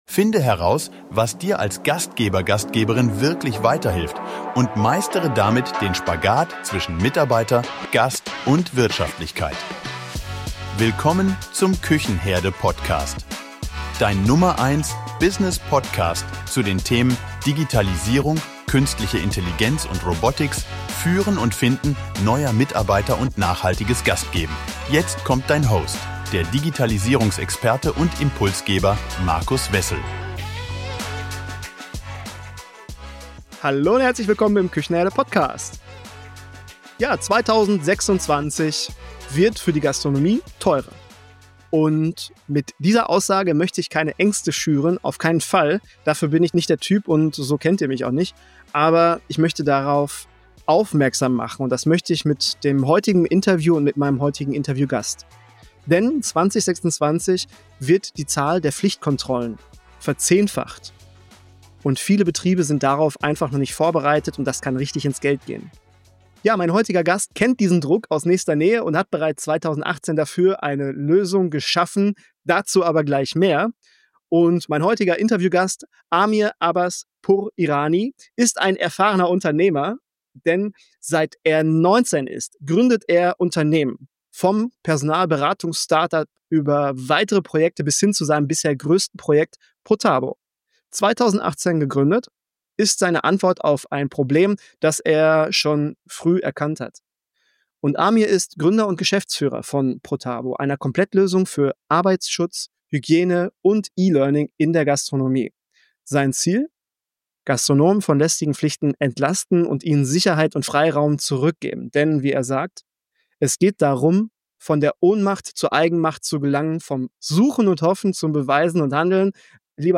Interview. Die Zahl der Pflichtkontrollen verzehnfacht sich von 0,5% auf 5% aller Betriebe pro Jahr.